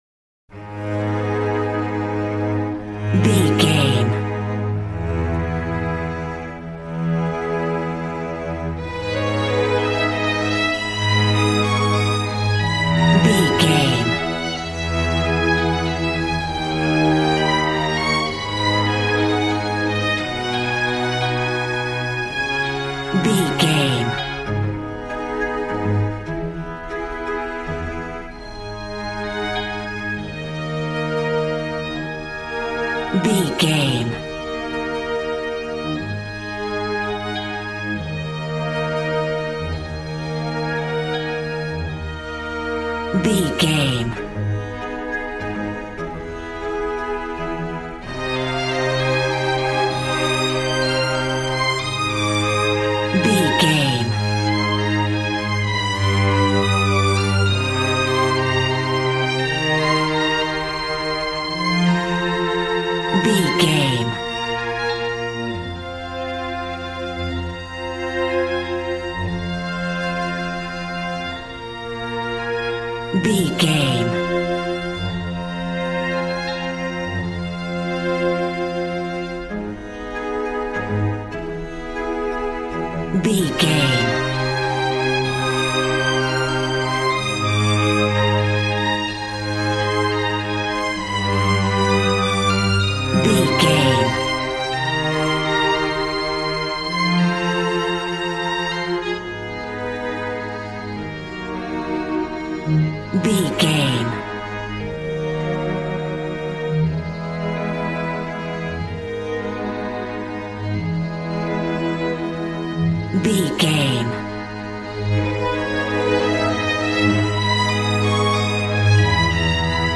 Ionian/Major
A♭
regal
brass